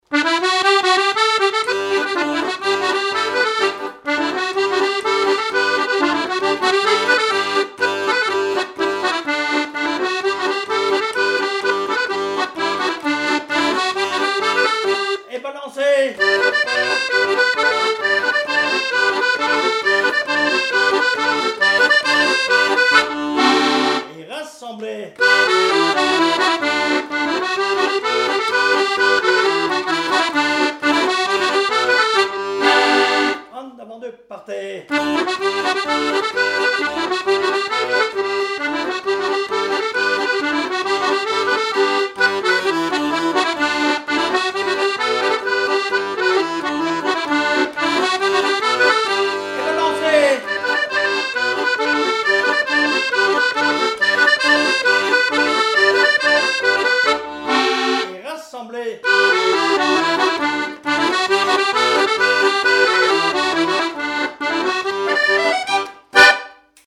Chants brefs - A danser
danse : branle : avant-deux
Pièce musicale inédite